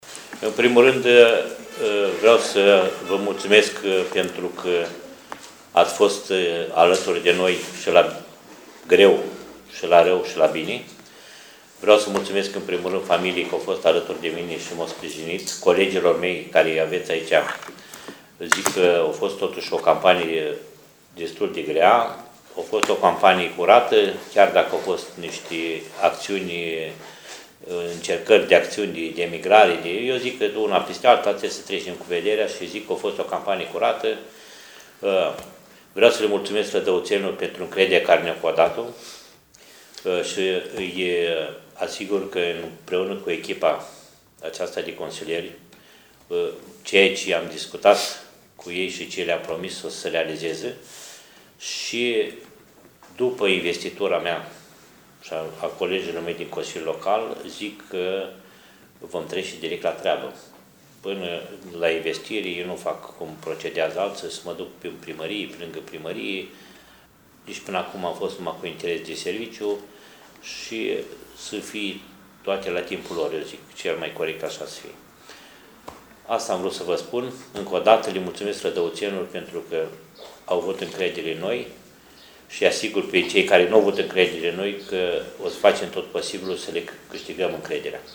Astăzi, primarul ales al municipiului Rădăuți, Nistor Tătar, a susținut o conferință de presă, în prezența membrilor și consilierilor PSD, partid din care face parte.